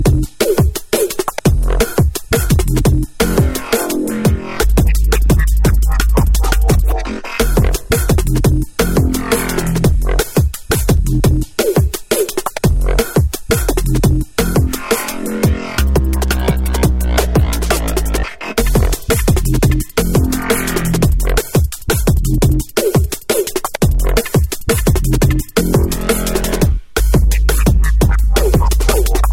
TOP >Vinyl >Drum & Bass / Jungle
TOP > HARD / TECH